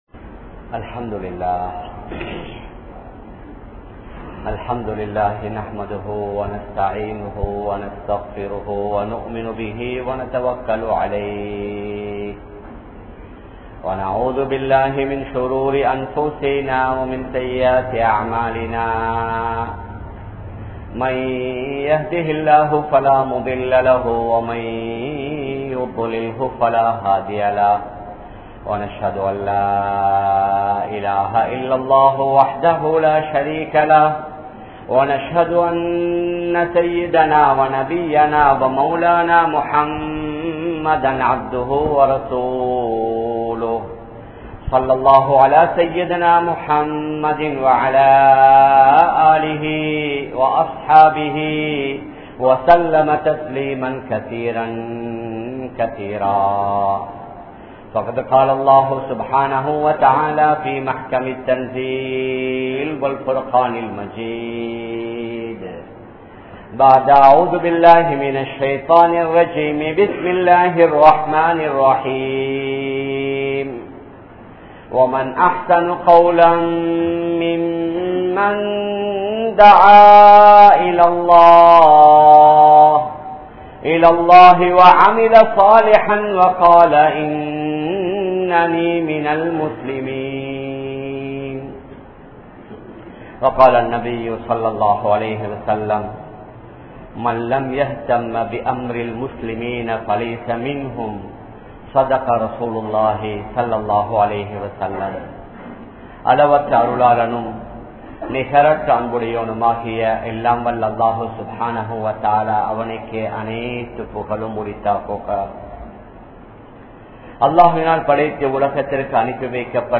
Dhauwathin Avasiyam (தஃவத்தின் அவசியம்) | Audio Bayans | All Ceylon Muslim Youth Community | Addalaichenai